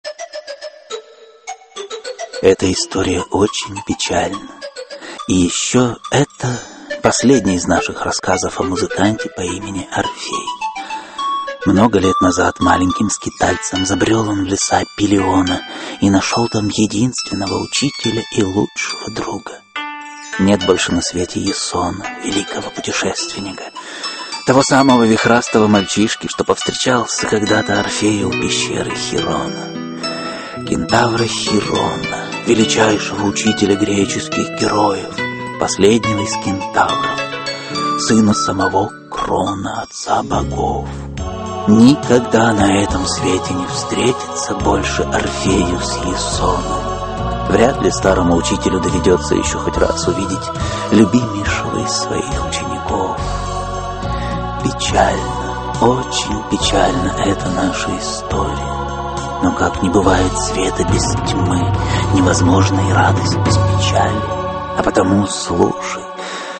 Аудиокнига Легенды и мифы Древней Греции. Орфей и Эвридика. Аудиоспектакль | Библиотека аудиокниг